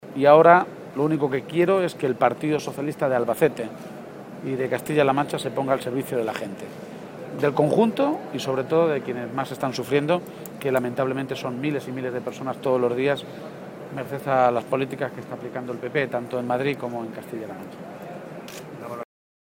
García-Page atiende a los medios de comunicación.
El secretario general del PSOE de Castilla-La Mancha, Emiliano García-Page, clausuró el Congreso provincial de los socialistas albaceteños
Garcia_Page-congreso_PSOE_AB-5.mp3